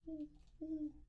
鹰鸮1
描述：另一个录音是在我们浴室的窗户上进行的，它面对着一片桉树林。这一次是在深夜，一切都很安静。鹰鸮在其中一棵树上发出的萦绕的声音。仔细听样本的最后部分，你会听到它的翅膀在起飞时抓住了一些树叶。用松下迷你DV摄像机和廉价的驻极体电容式话筒录制。
声道立体声